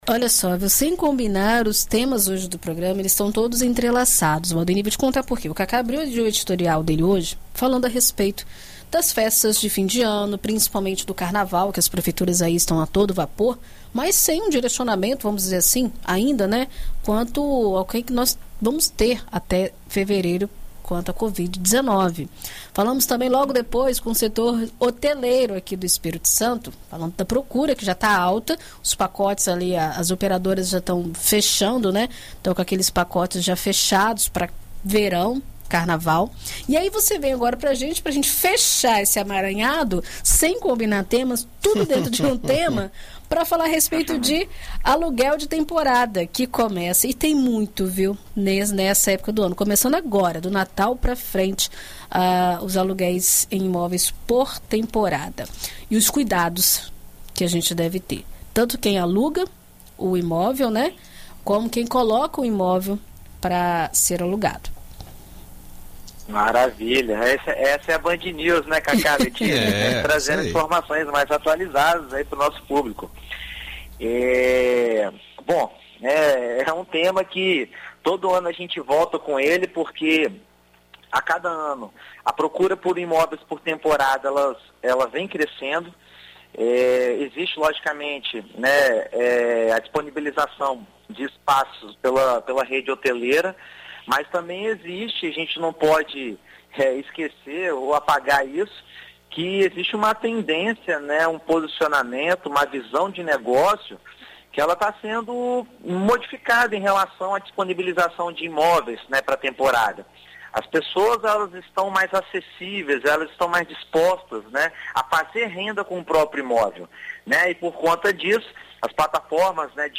Na coluna Seu Imóvel desta terça-feira (23), na BandNews FM Espírito Santo